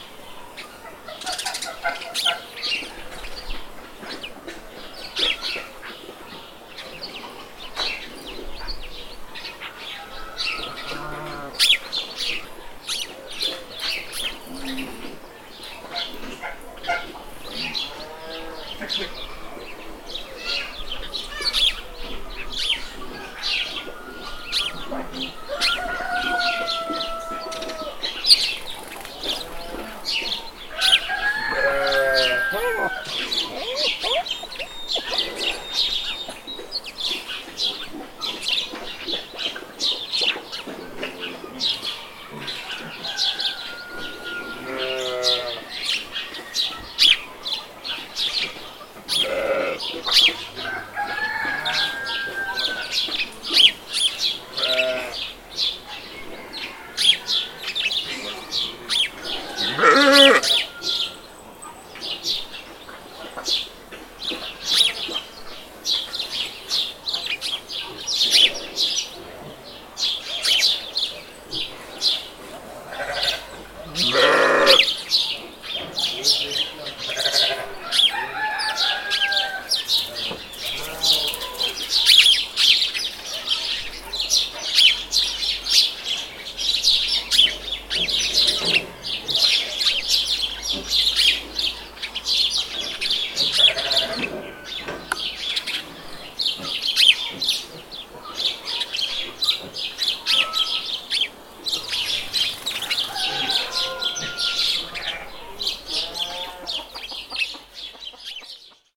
دانلود آهنگ مزرعه از افکت صوتی طبیعت و محیط
دانلود صدای مزرعه از ساعد نیوز با لینک مستقیم و کیفیت بالا
جلوه های صوتی